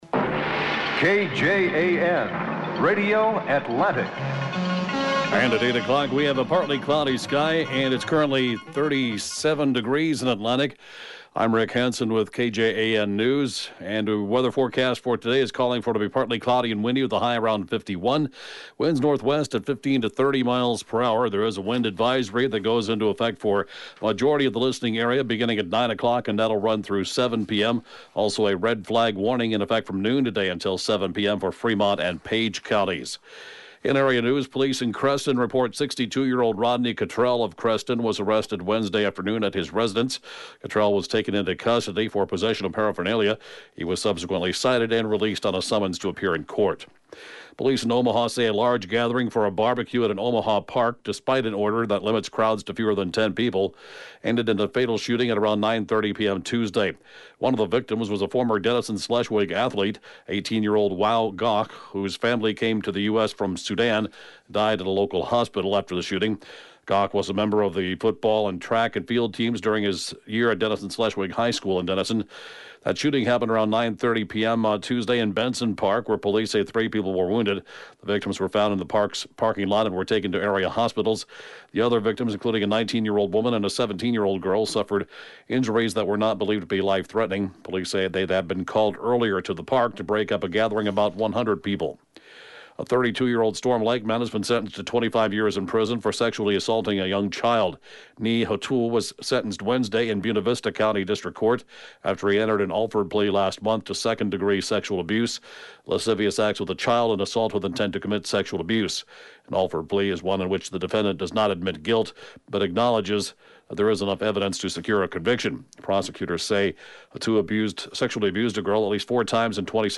(Podcast) KJAN 8-a.m. News, 12/21/2018